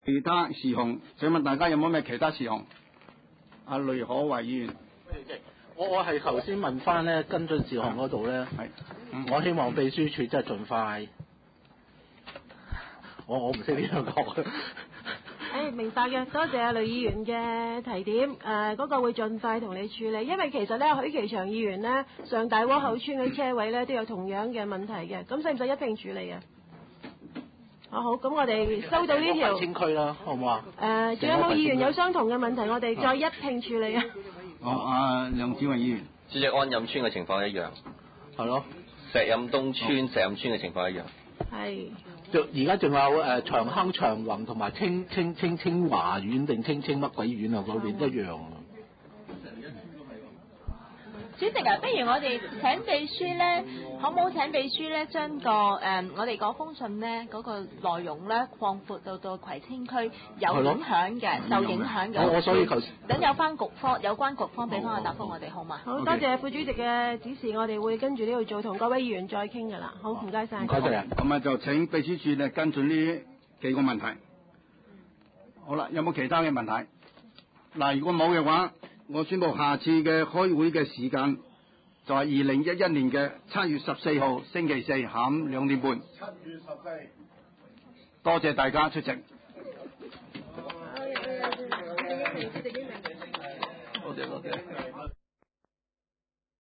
葵青區議會第七十一次會議